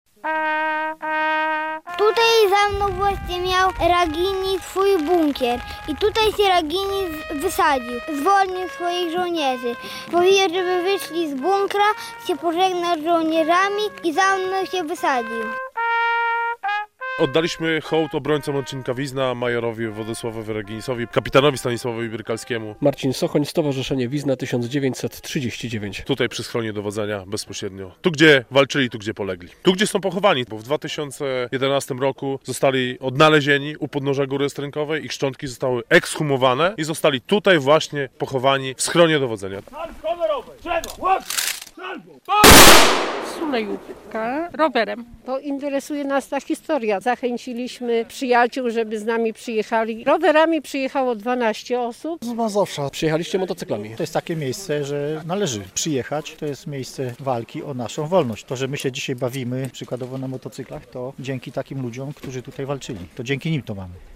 relacja
- To dzięki takim żołnierzom jak ci, którzy polegli w polskich Termopilach możemy cieszyć się wolnością - mówili uczestnicy rocznicowych uroczystości na Górze Strękowej koło Wizny.